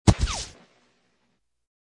bullet.2.ogg